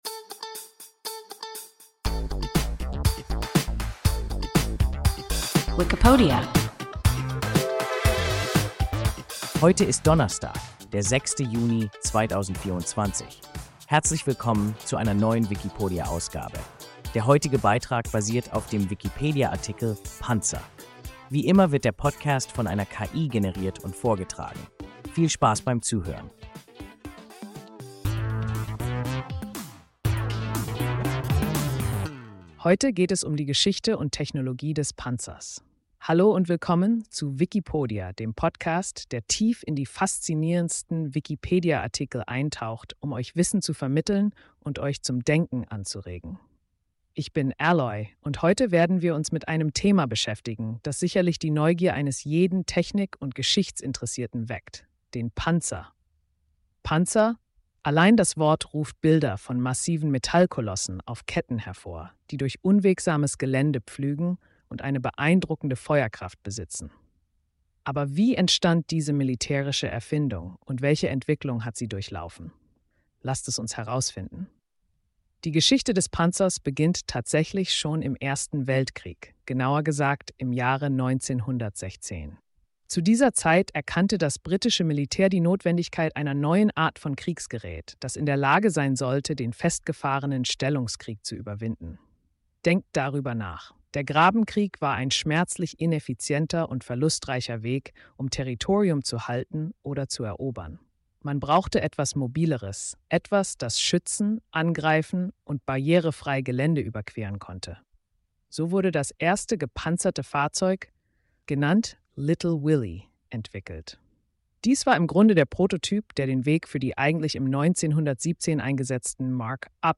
Panzer – WIKIPODIA – ein KI Podcast